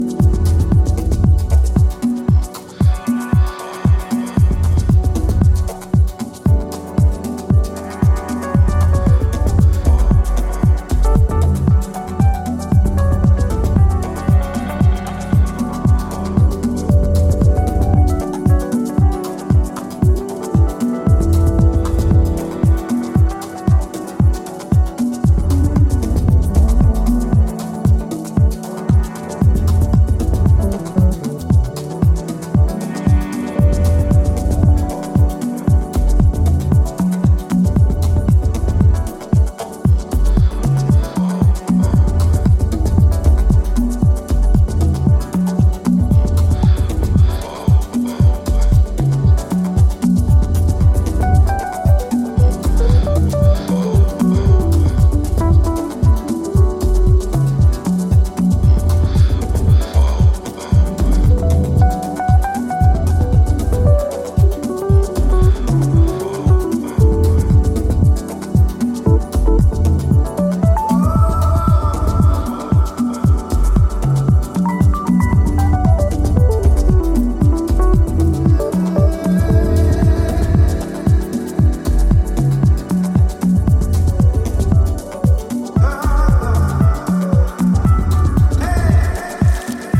spaced out textures and riffs